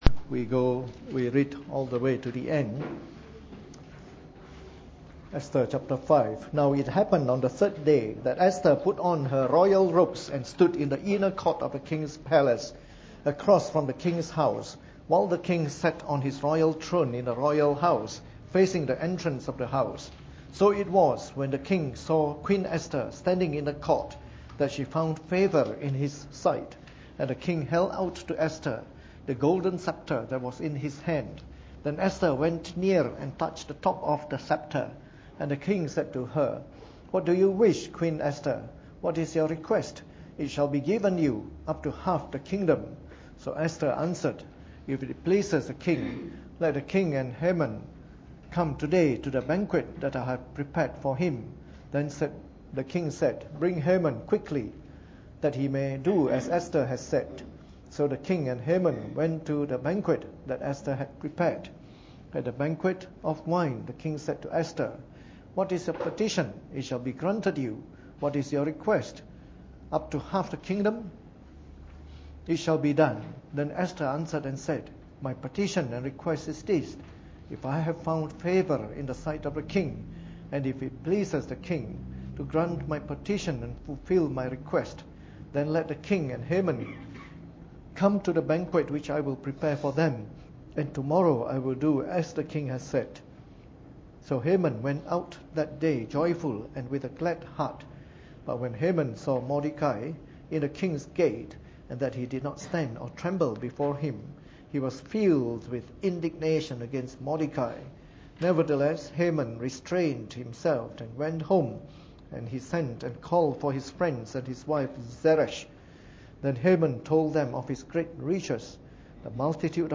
Preached on the 30th of October 2013 during the Bible Study, from our series of talks on the Book of Esther.